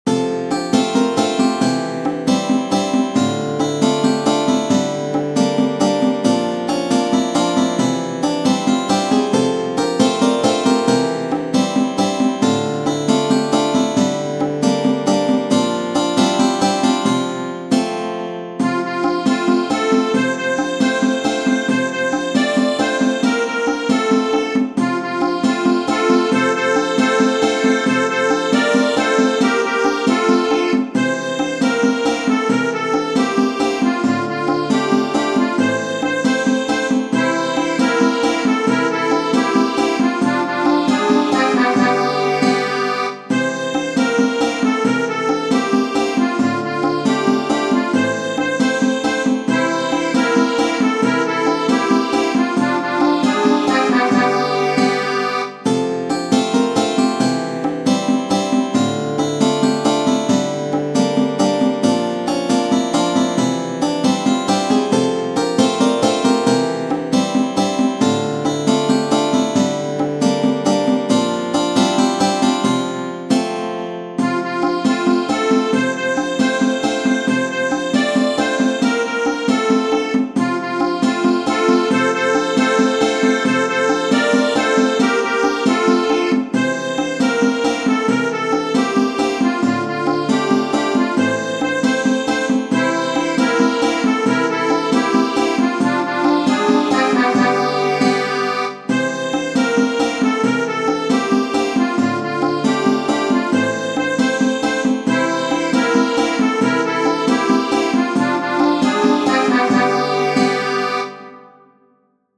Tradizionale Genere: Folk Canzone folk originaria della regione del Pirin in Bulgaria, spesso utilizzata come una danza.